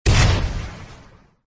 Oildrum_explosion.mp3